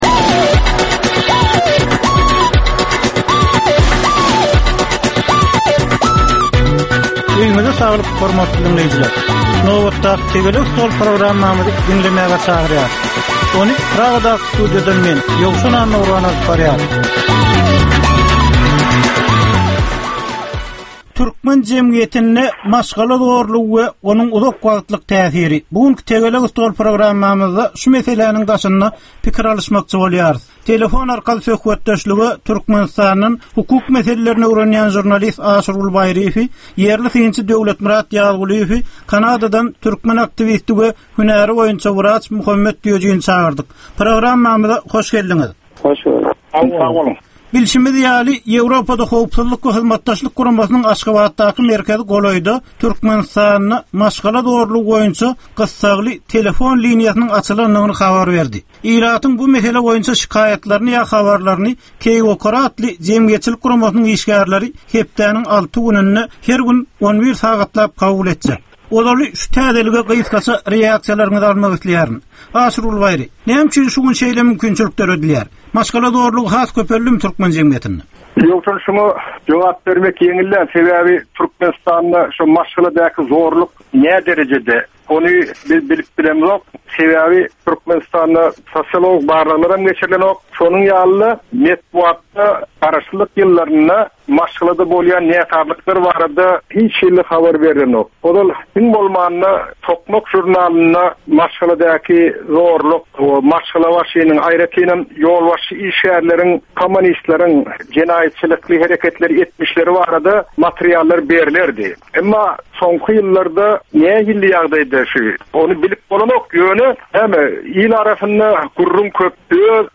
Jemgyýetçilik durmuşynda bolan ýa-da bolup duran soňky möhum wakalara ýa-da problemalara bagyşlanylyp taýyarlanylýan ýörite Tegelek stol diskussiýasy. 30 minutlyk bu gepleşikde syýasatçylar, analitikler we synçylar anyk meseleler boýunça öz garaýyşlaryny we tekliplerini orta atýarlar.